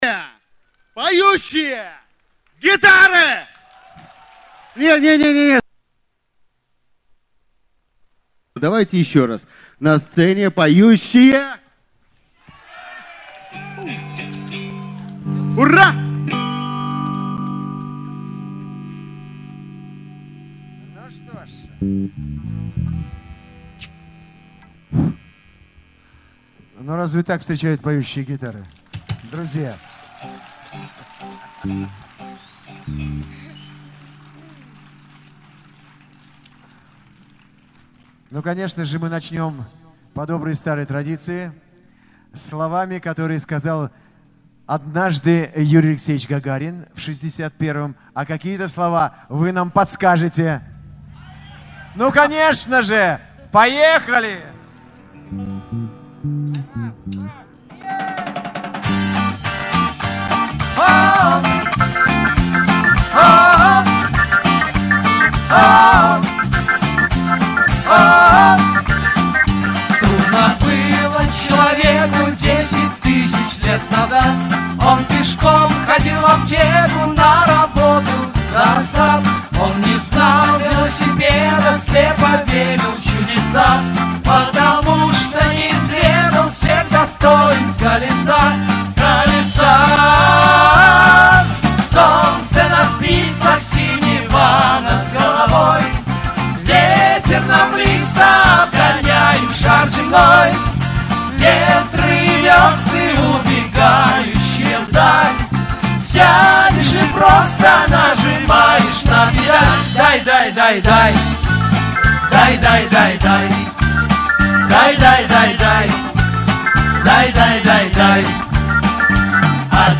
Запись этого концерта: